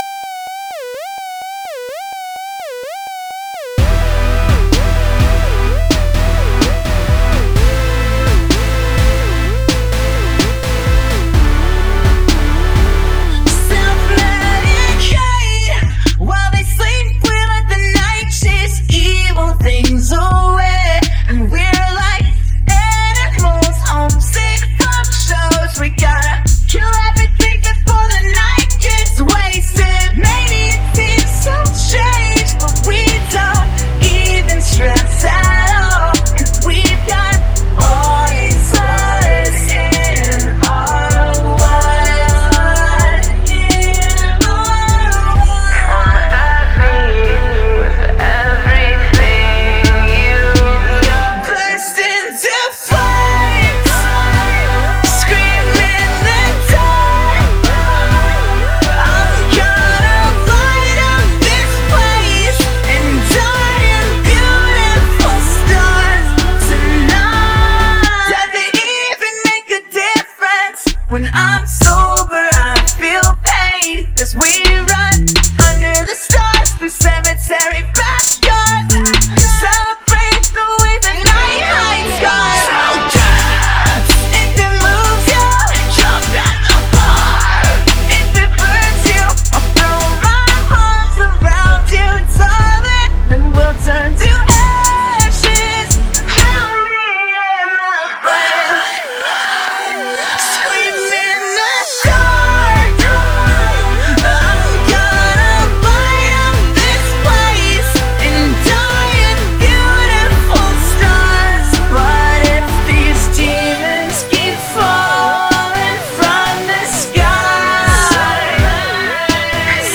That one sided sound at the start LOL